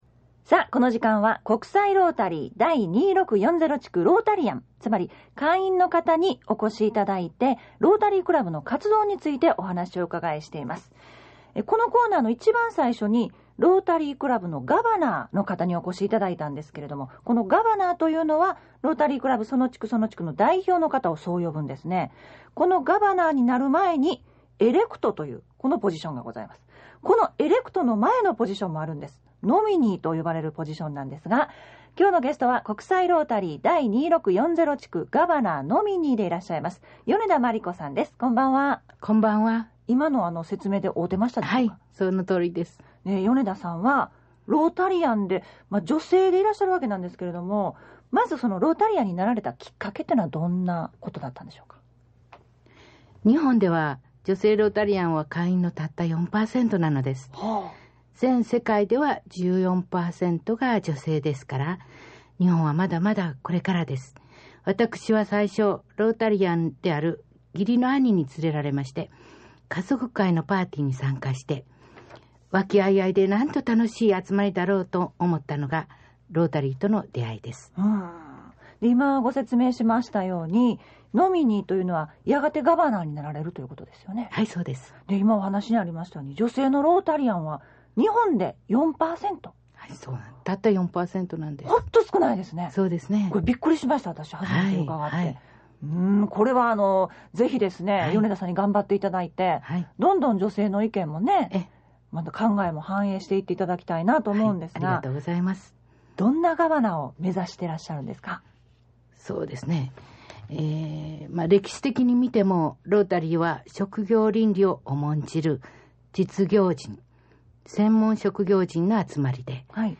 ラジオ大阪放送
2008年12月からラジオ大阪（OBC）で放送された内容を掲載しています。